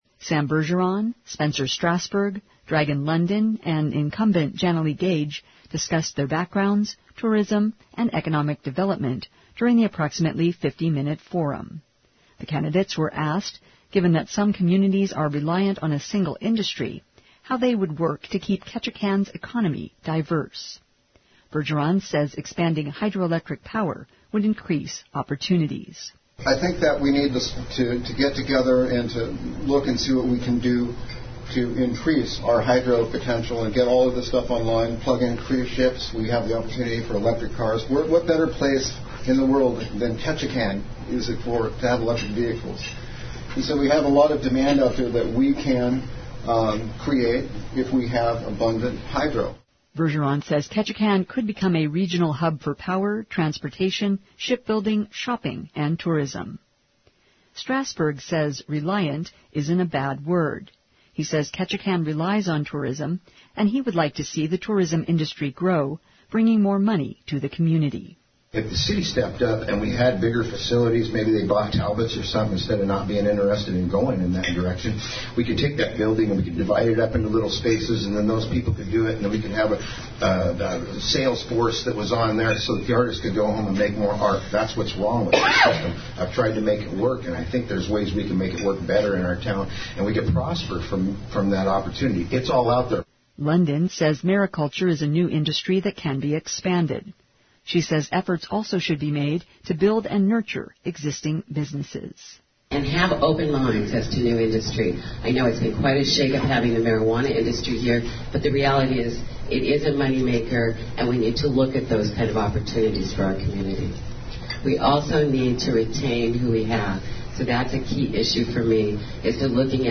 Candidates for Ketchikan City Council participated in a forum at Wednesday’s Chamber of Commerce luncheon.